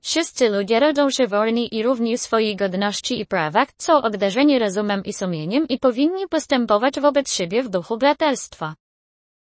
AI Voice in Polish
Polish-TTS.mp3